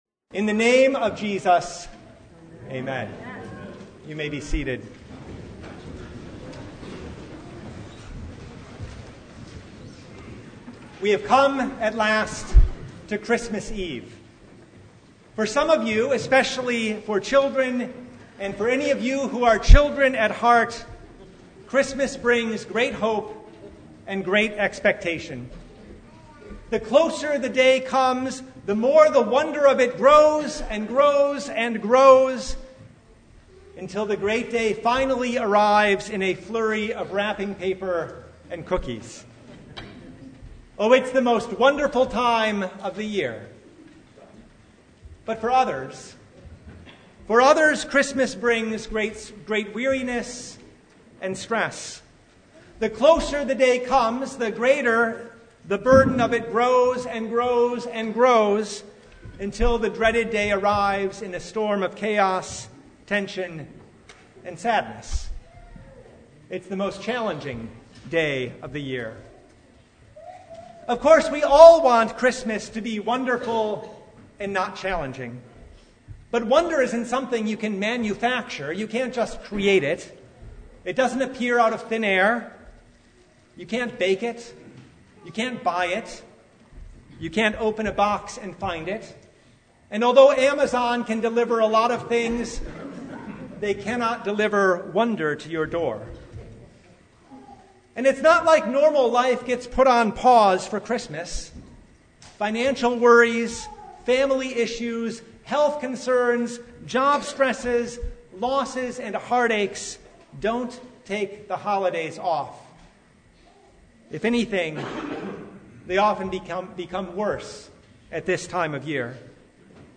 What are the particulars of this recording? Service Type: Christmas Eve Vespers